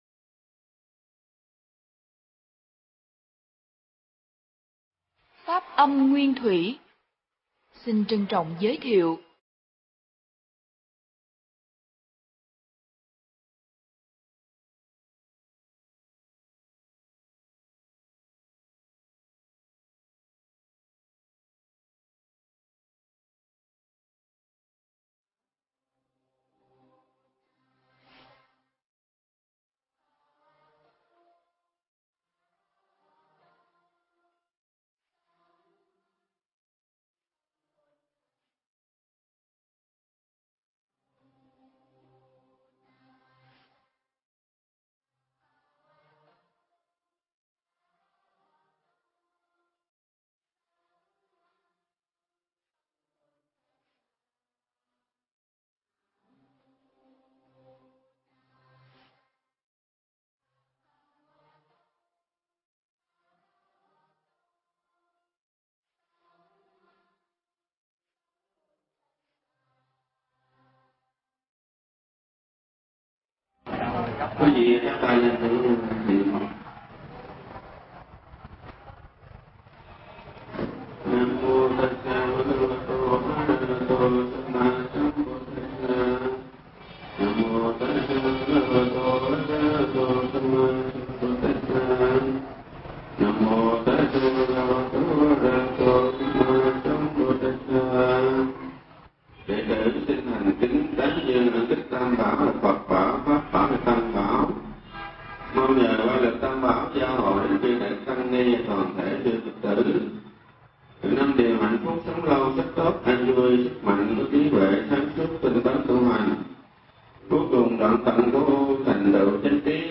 Nghe Mp3 thuyết pháp Tri Ân Và Trách Móc